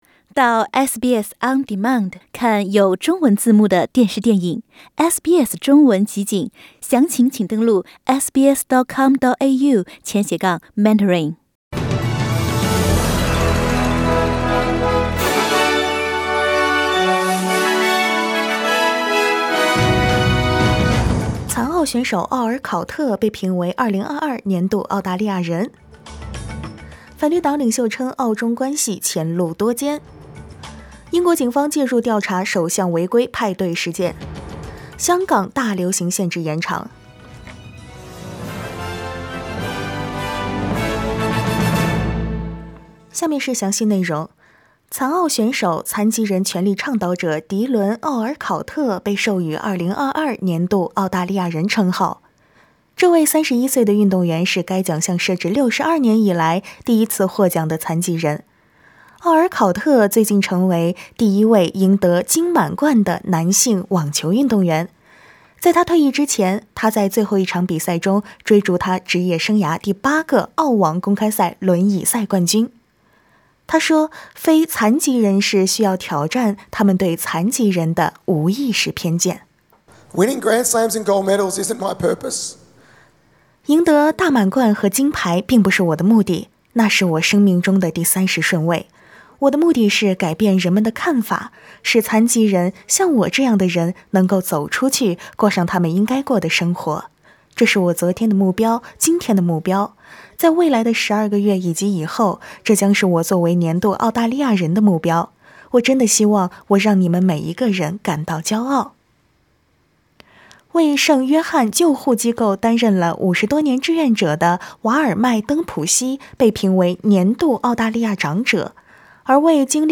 SBS早新闻（1月26日）
SBS Mandarin morning news Source: Getty Images